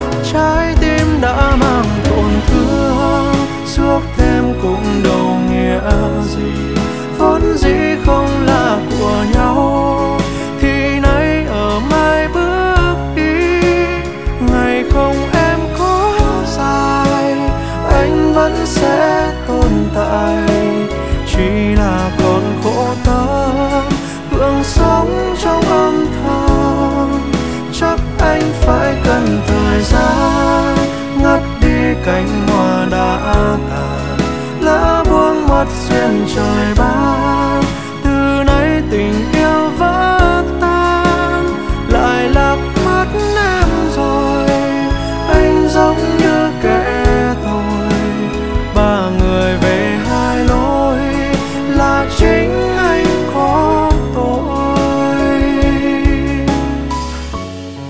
Nhạc chuông 47 lượt xem 08/03/2026